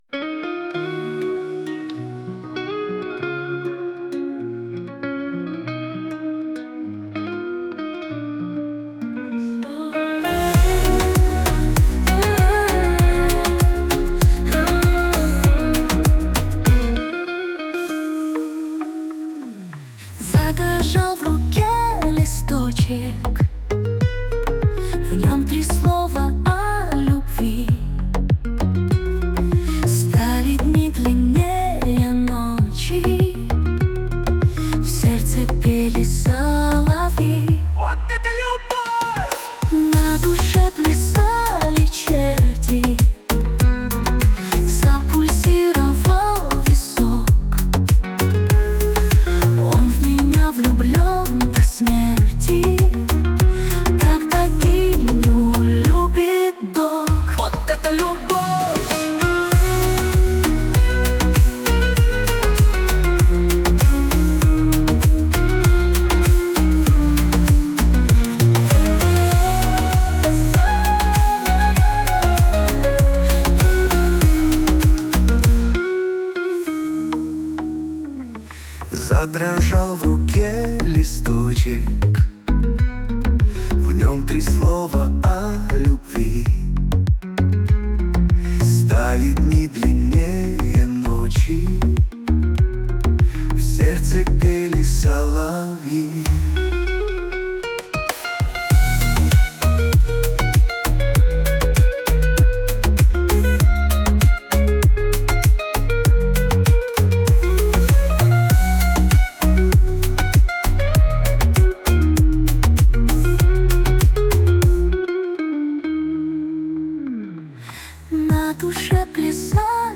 Музыкальный хостинг: /Бардрок